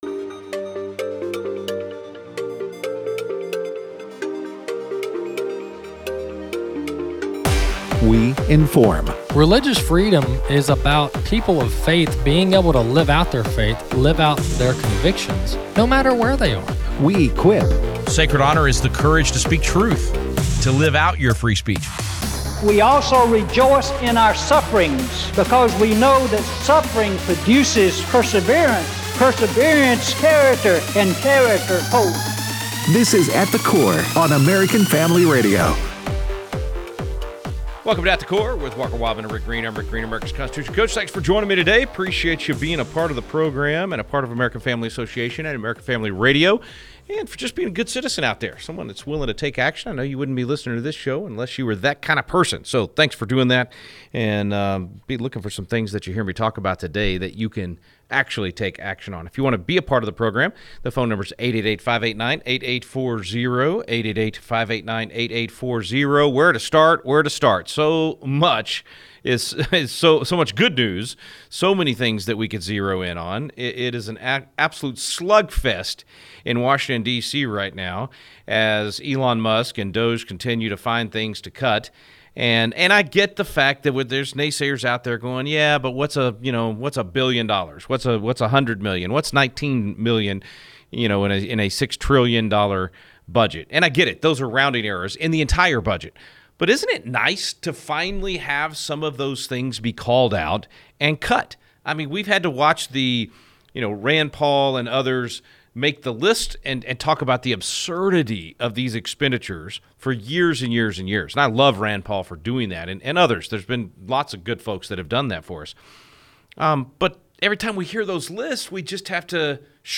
Callers weigh in